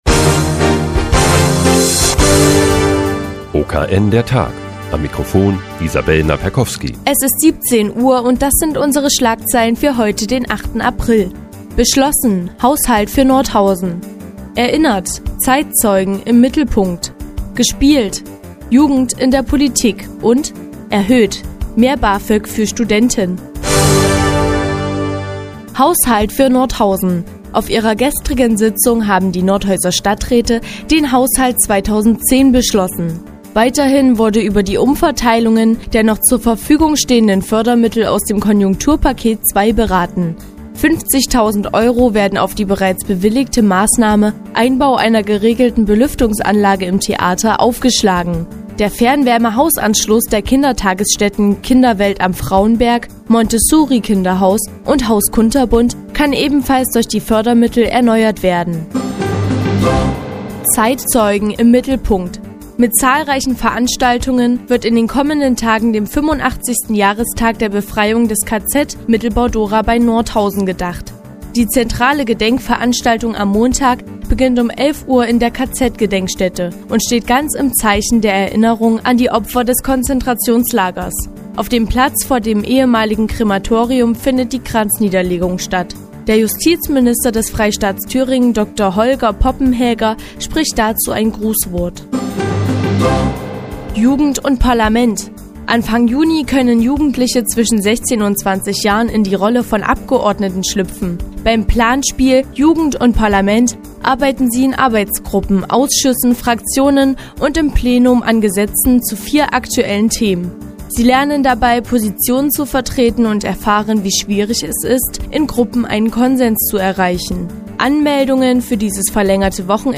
Die tägliche Nachrichtensendung des OKN ist nun auch in der nnz zu hören. Heute geht es um den Haushaltsplan 2010 für Nordhausen und neue Bafög-Sätze für Studenten.